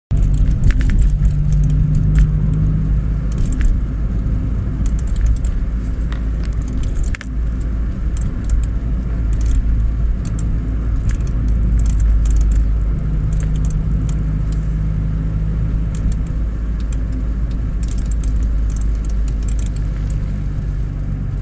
Habe mal das Knistern aufgenommen, wie gesagt es ist nicht dauerhaft.. die erste halbe Stunde ist es gar nicht aufgetreten, und dann rastet es mal wieder für 20-30sec aus.
knisterino.mp3
Hört sich wie ein Relais an, dass immer schaltet.
Klingt total nach Relais klackern.